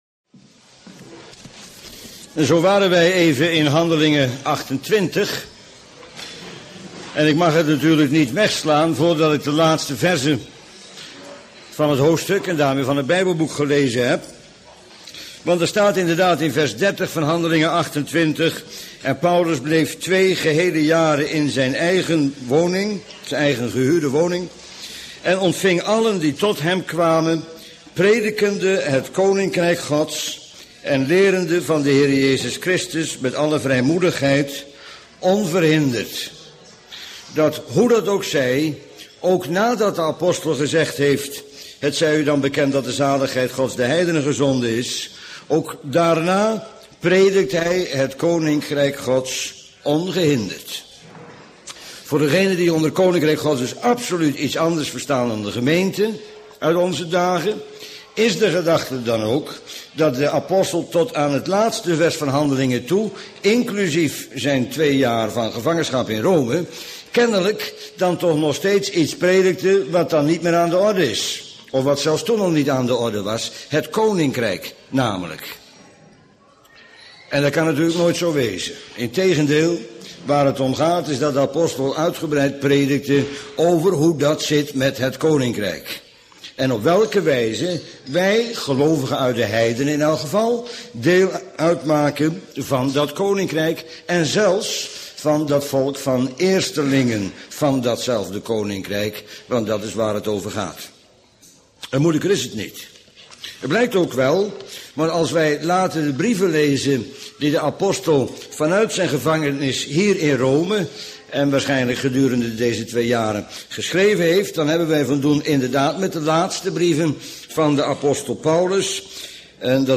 Bijbelstudie lezingen mp3.